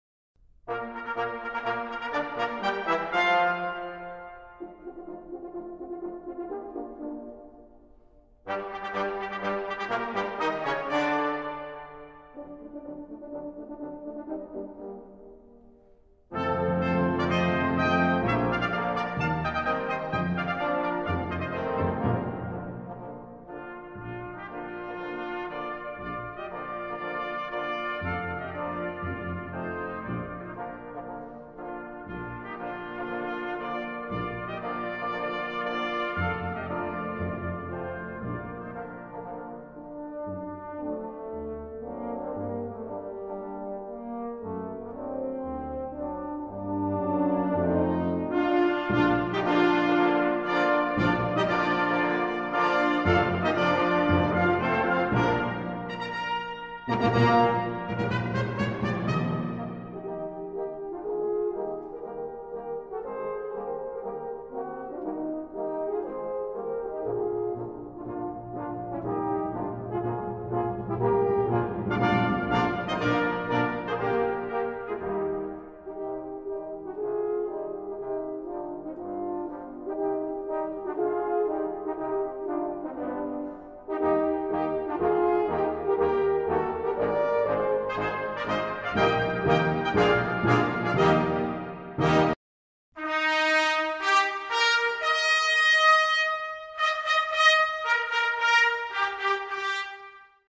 в инструментальном исполнении